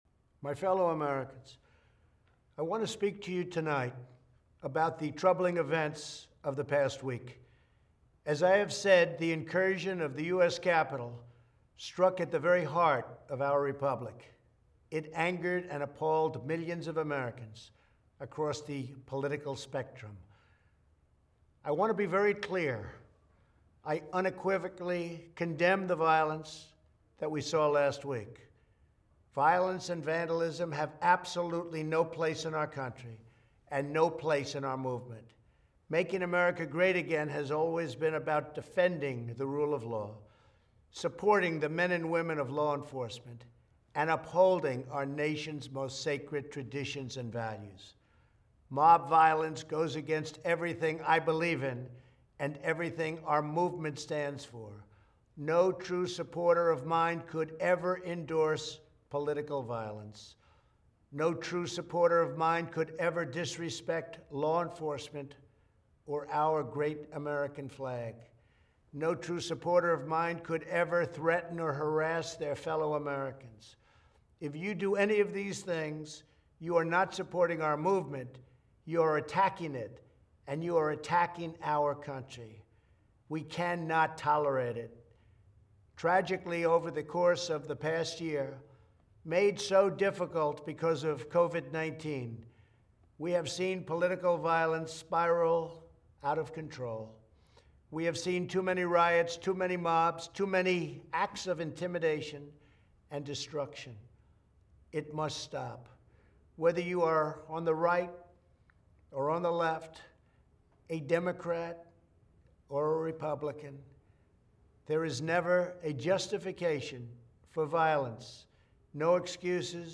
Presidential Speeches
message_from_trump.mp3